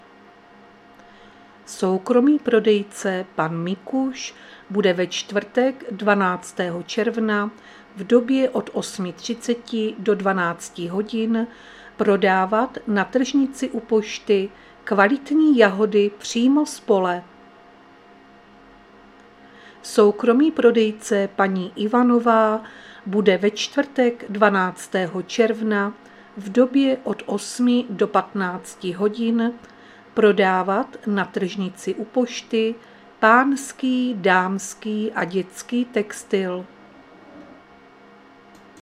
Záznam hlášení místního rozhlasu 11.6.2025
Zařazení: Rozhlas